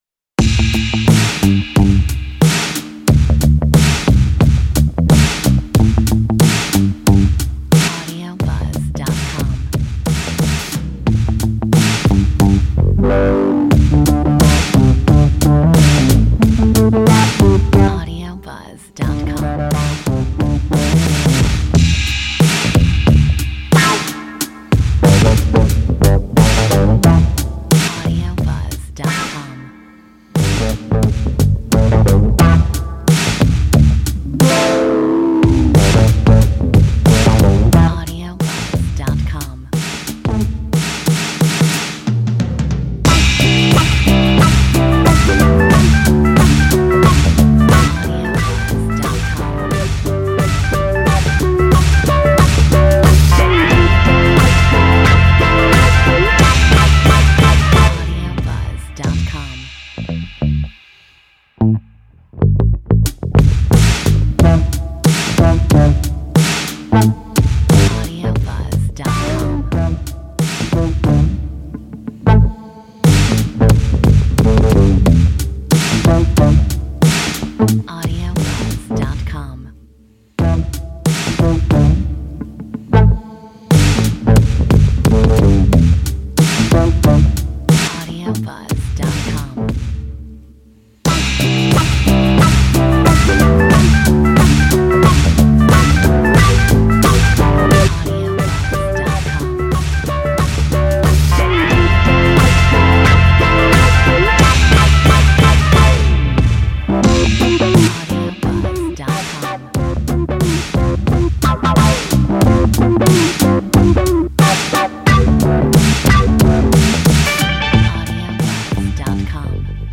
Metronome 90